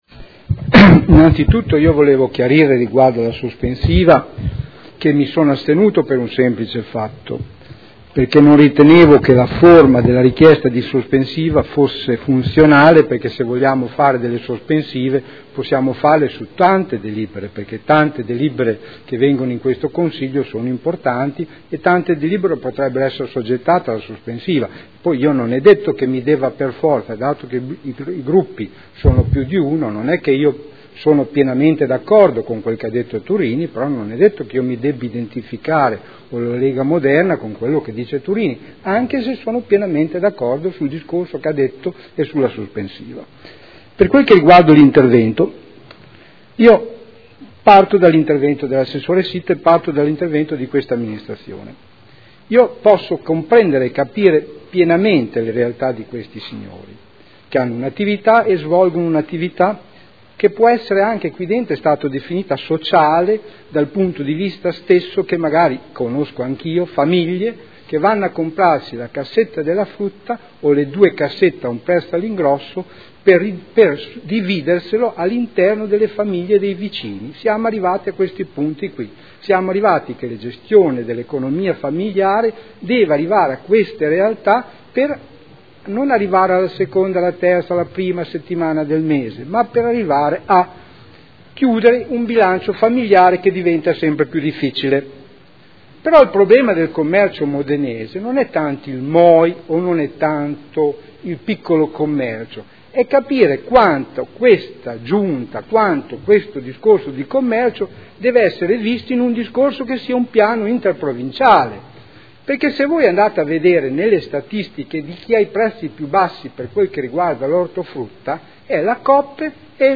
Seduta del 18/06/2012. Dibattito su proposta di deliberazione e ordine del giorno.